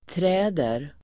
Uttal: [tr'ä:der]